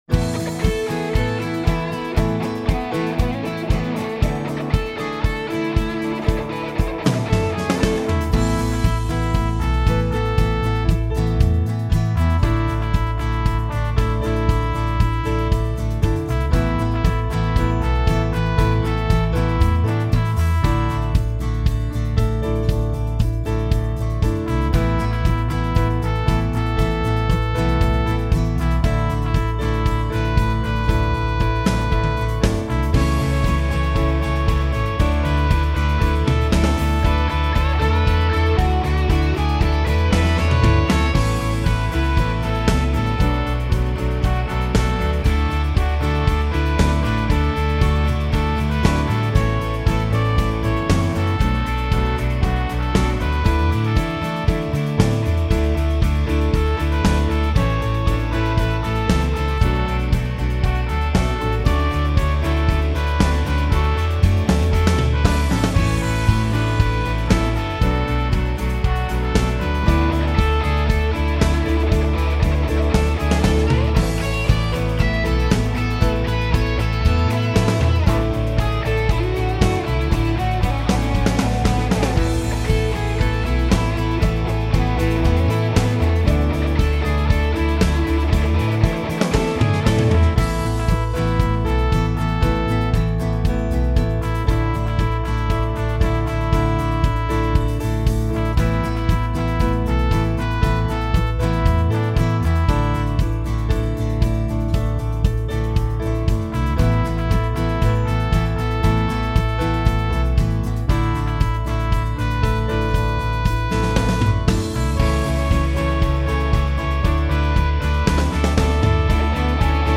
Or you could just sing a long to my backing.